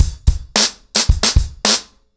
BlackMail-110BPM.17.wav